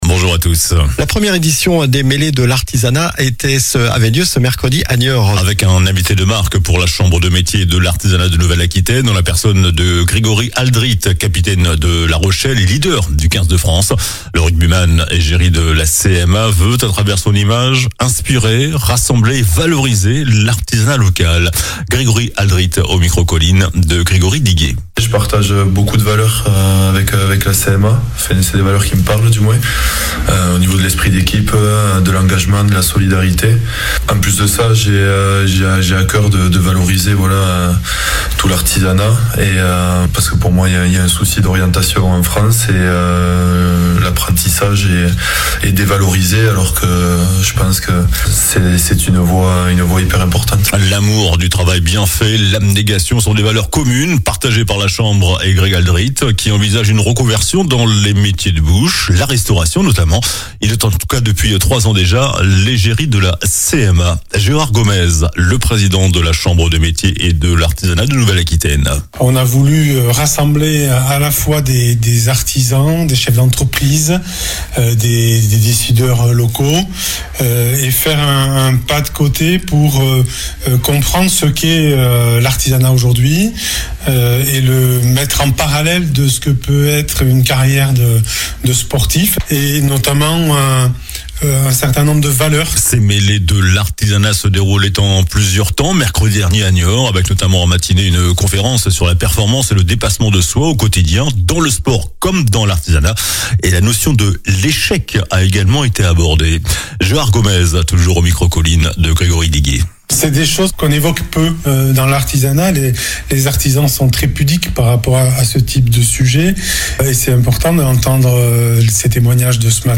Journal du samedi 10 mai
infos locales